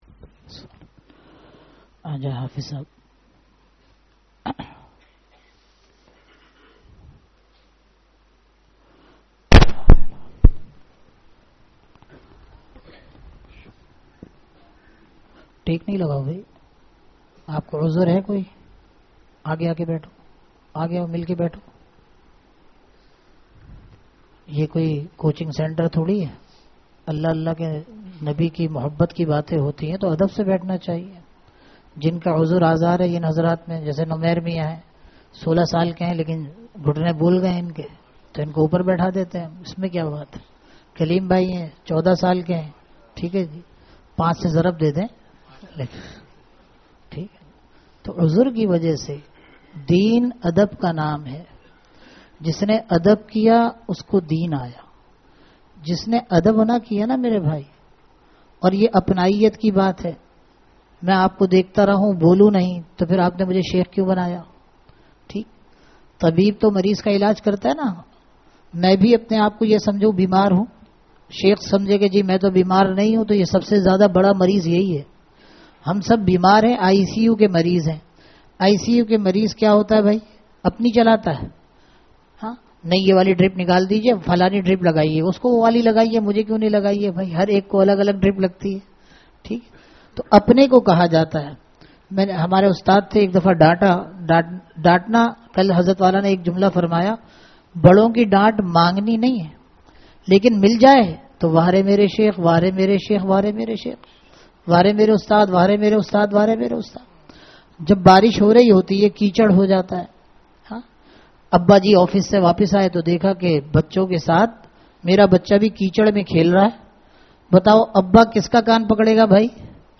Bayanat